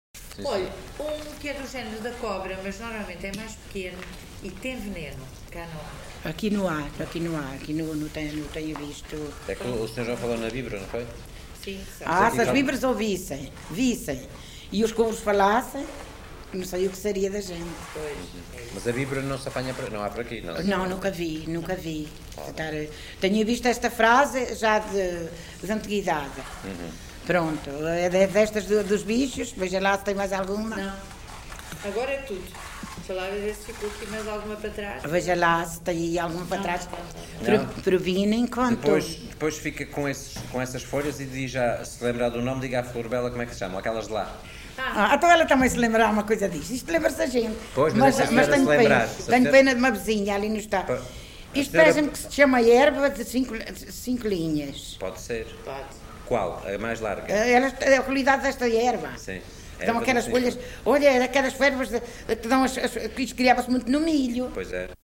LocalidadeVila Pouca do Campo (Coimbra, Coimbra)